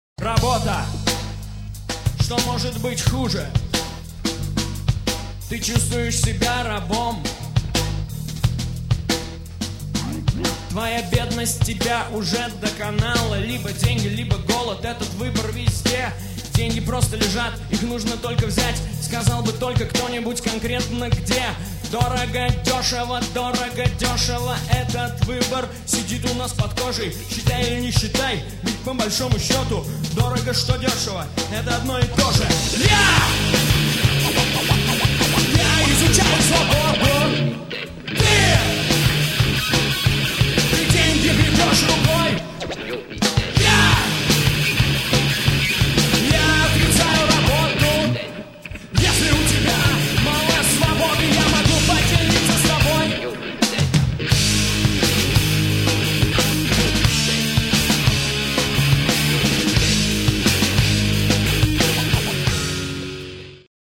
Шаболовка (1997)
фрагмент песни (1 мин. 04 сек.)
AUDIO, stereo